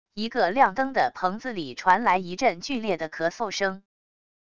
一个亮灯的棚子里传来一阵剧烈的咳嗽声wav音频